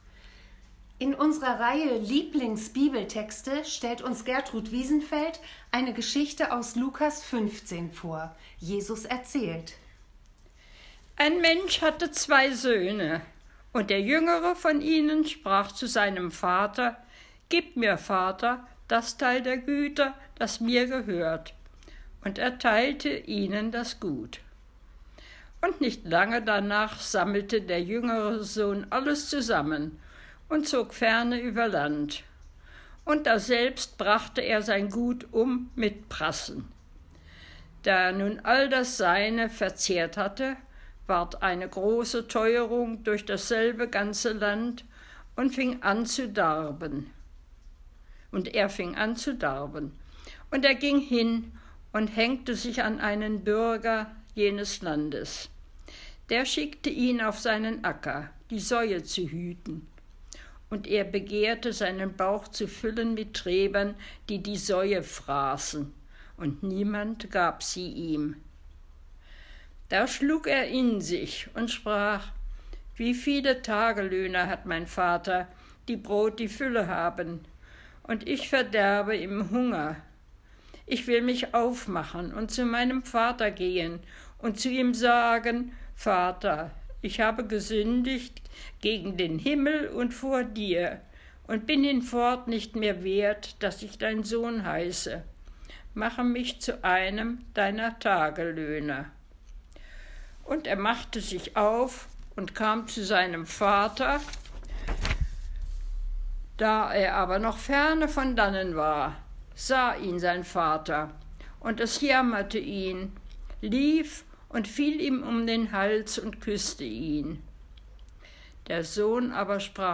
gelesen und kommentiert